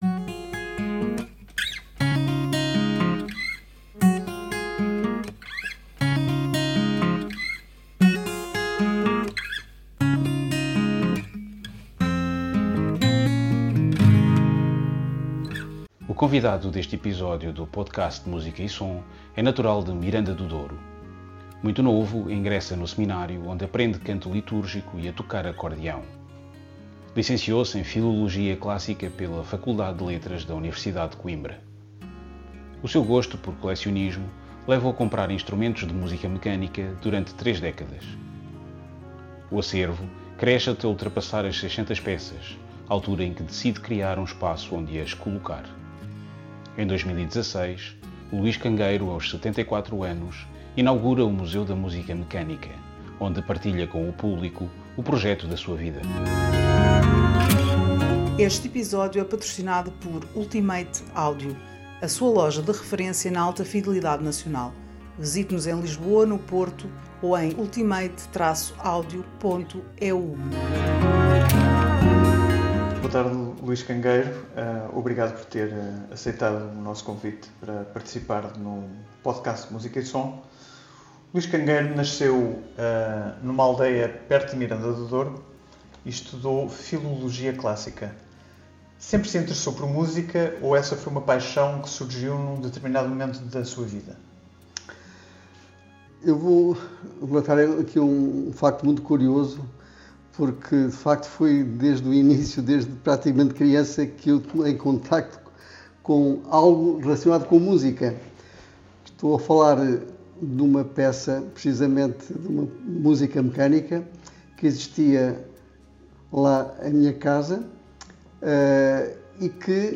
Nota: a partir do minuto 37, o podcast leva-nos numa visita guiada ao Museu da Música Mecânica, onde podemos desfrutar de alguns dos sons das caixas de música e "máquinas falantes" que fazem parte do seu acervo.